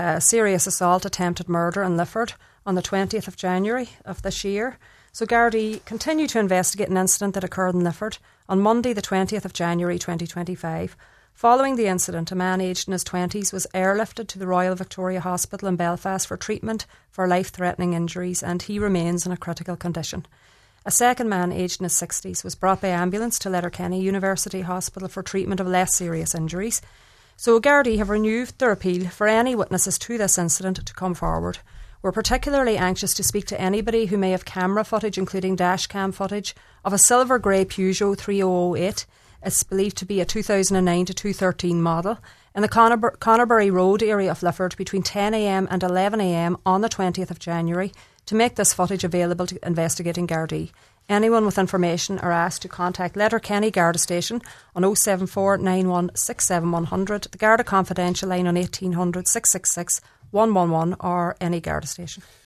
made the appeal on today’s Nine til Noon Show